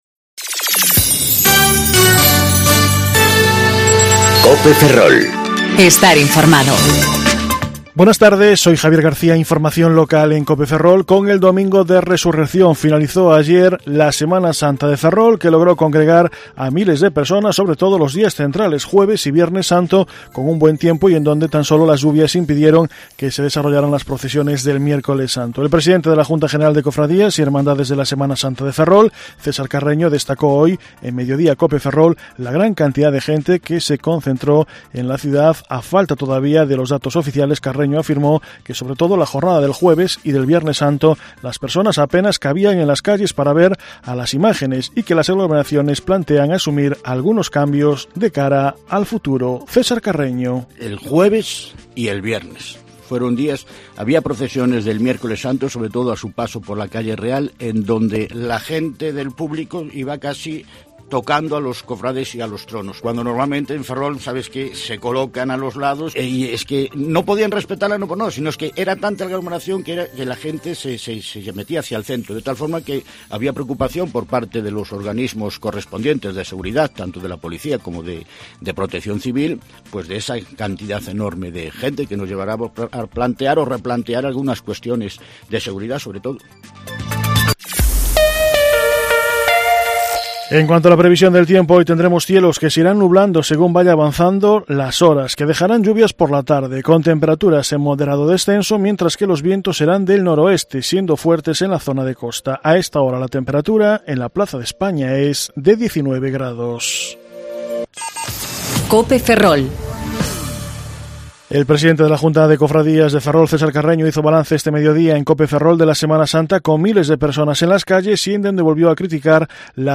Informativo Mediodía Cope Ferrol 22/04/2019 (De 14,20 a 14,30 horas)